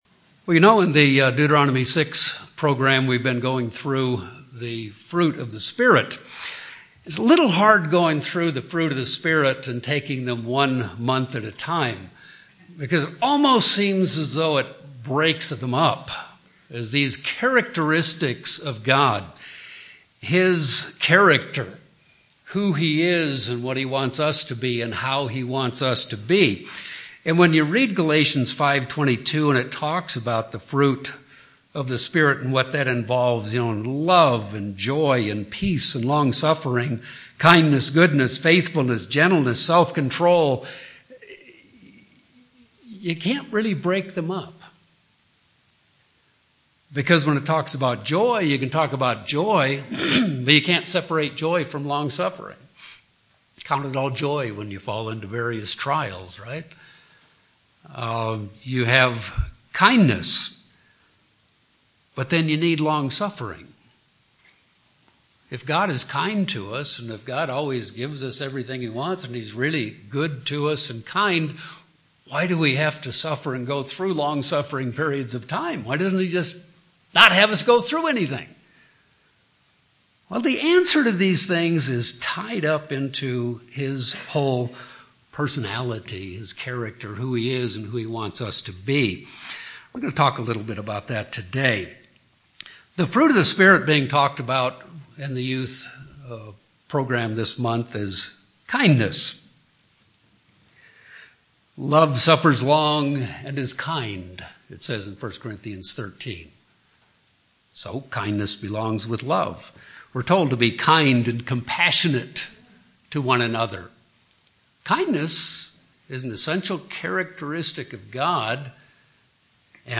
Sermons
Given in Albuquerque, NM